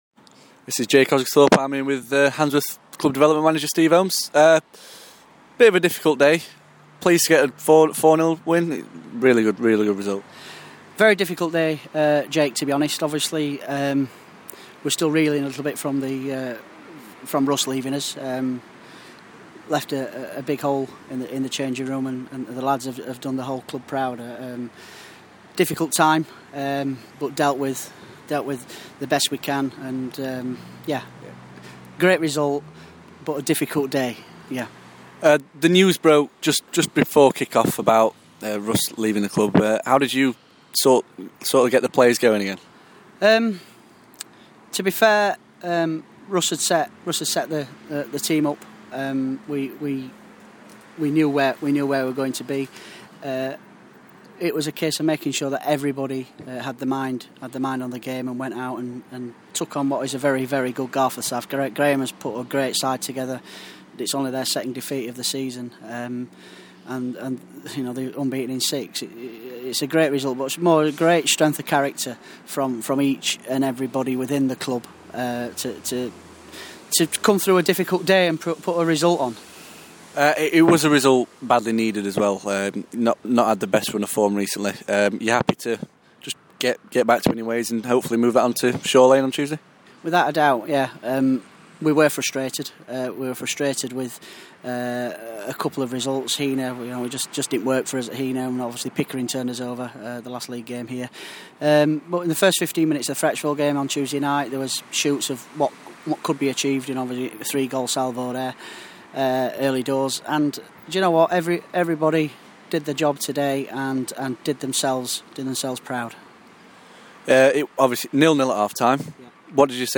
Club development manager gives his opinion on what's been a tough day for the club